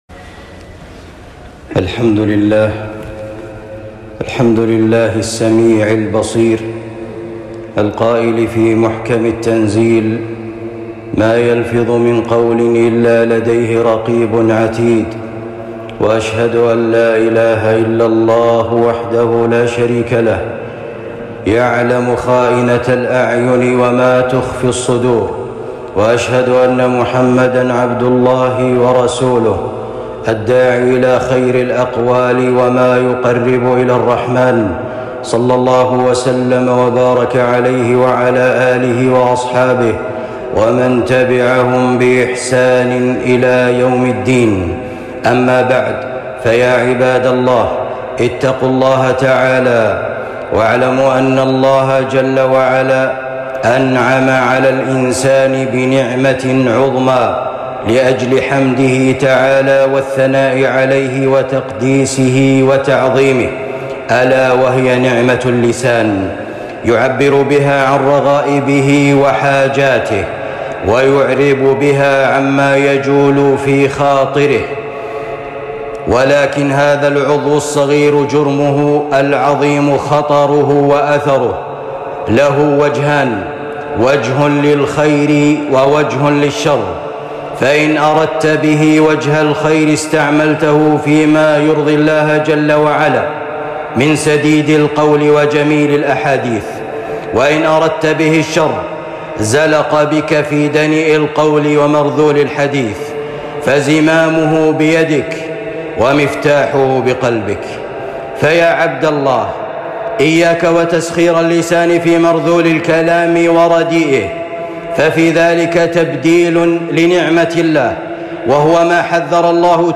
خطبة جمعة بعنوان ولا يغتب بعضكم بعضًا